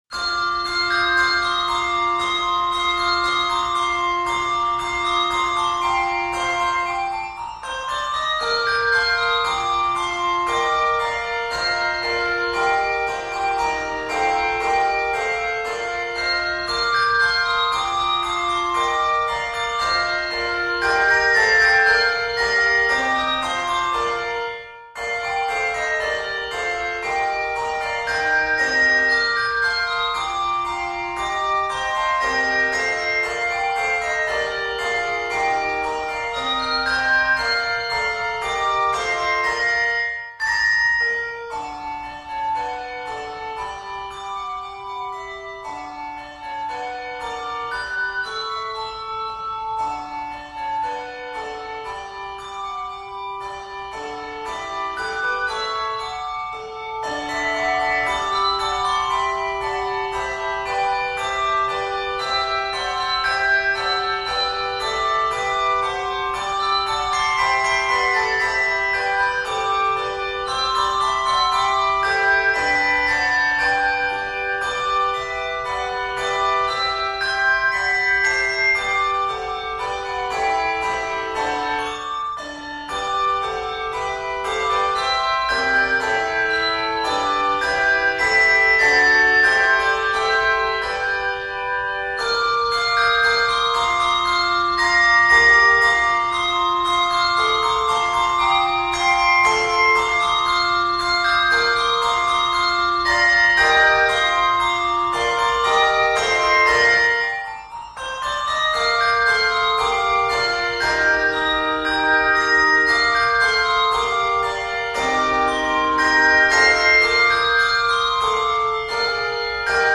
Octaves: 2-3